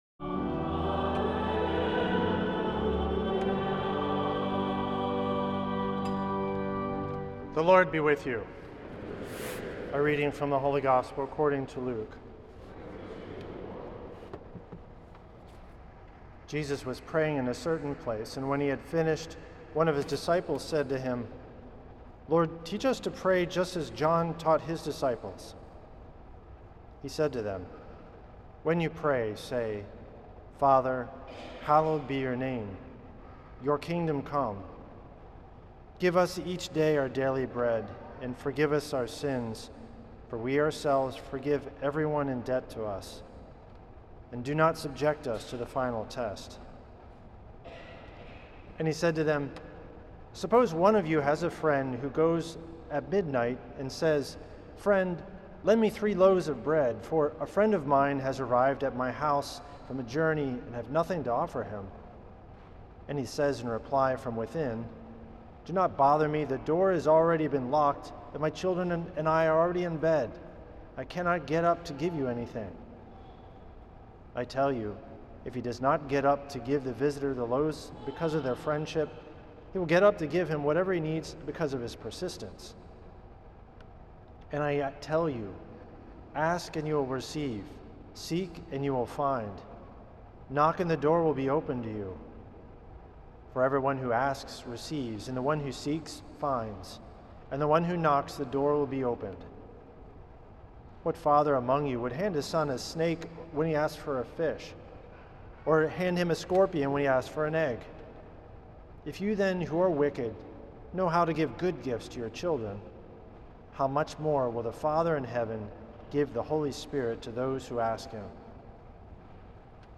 Homily
at St. Patrick’s Old Cathedral in NYC on July 26th 2025.